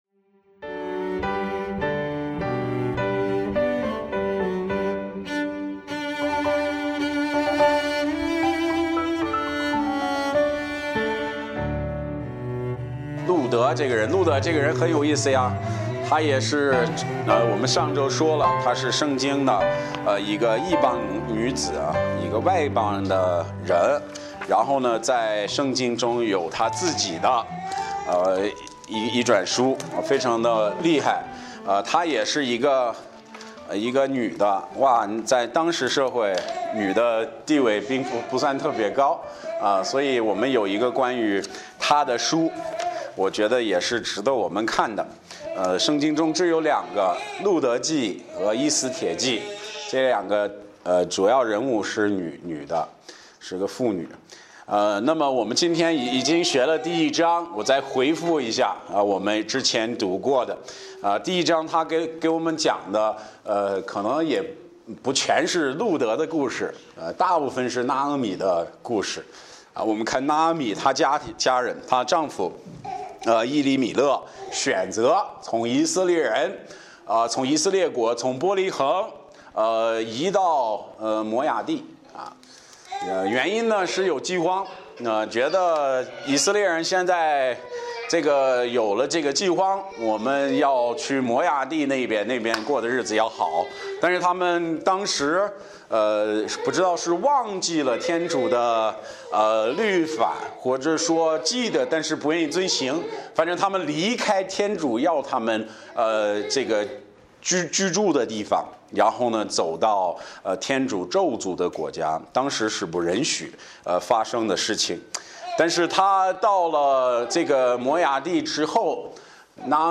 Bible Text: 路得记 2:1-23 | 讲道者